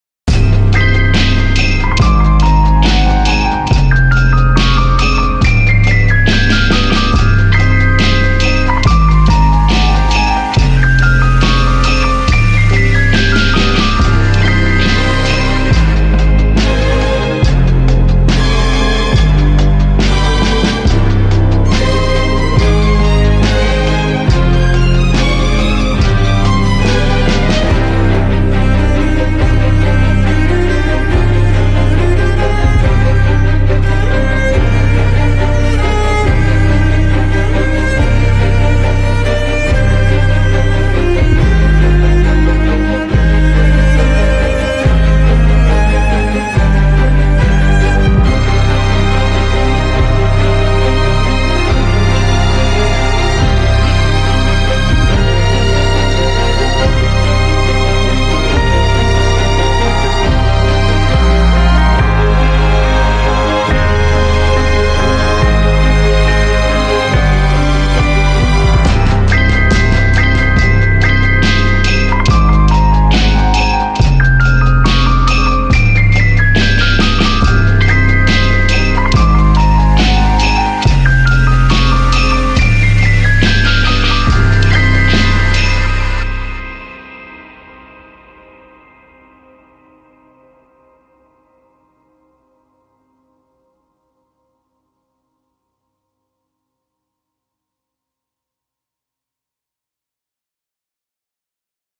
Belle musique orchestrale pour cette saga familiale.